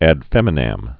(ăd fĕmĭ-năm, -nəm)